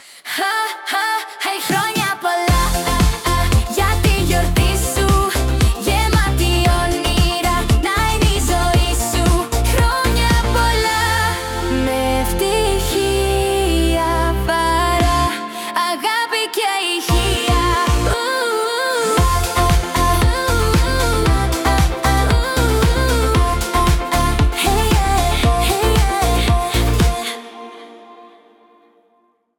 Απολαύστε αυτό το γλυκό τραγουδάκι γιορτής, Χρόνια Πολλά!